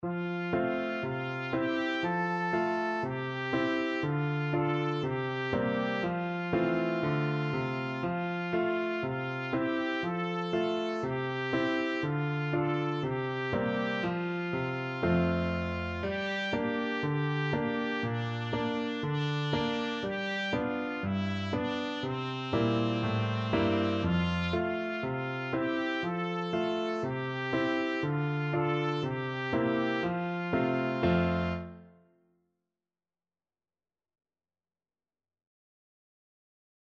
Traditional Trad. Au claire de la lune Trumpet version
Trumpet
F major (Sounding Pitch) G major (Trumpet in Bb) (View more F major Music for Trumpet )
4/4 (View more 4/4 Music)
Allegro (View more music marked Allegro)
Traditional (View more Traditional Trumpet Music)